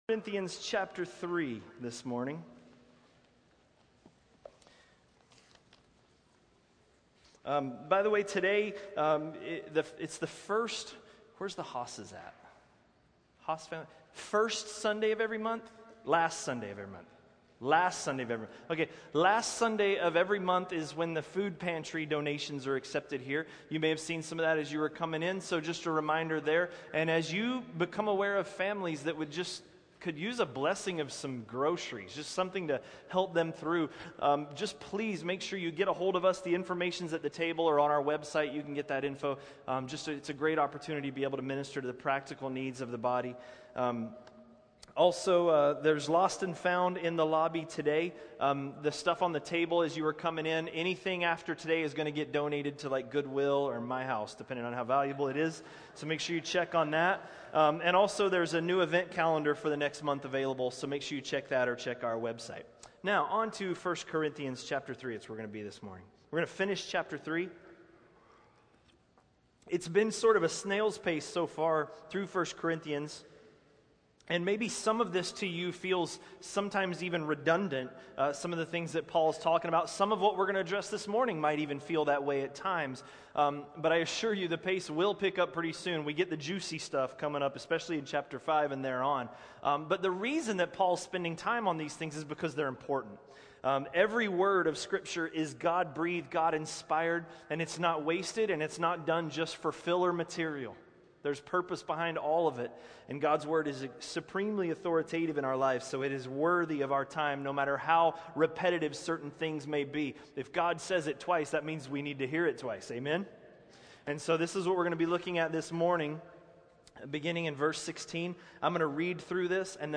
A message from the series "1 Corinthians." 1 Corinthians 3:16–3:23